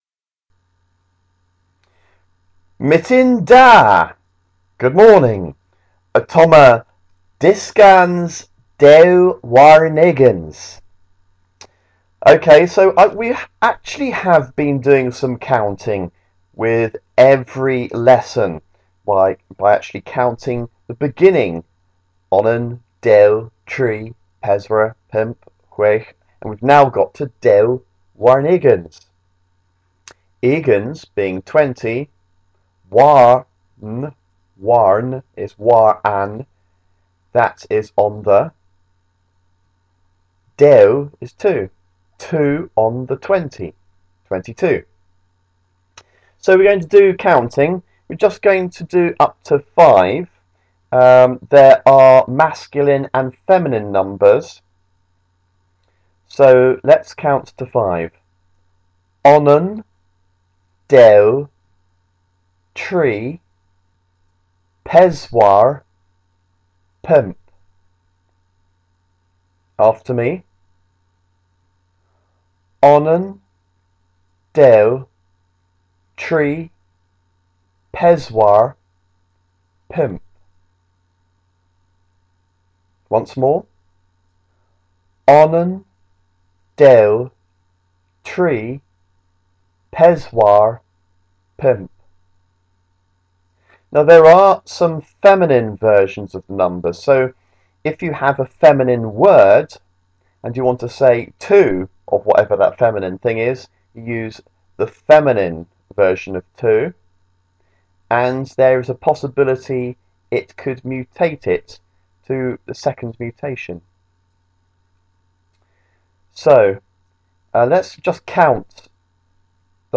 Cornish lesson 22 - dyskans dew warn ugens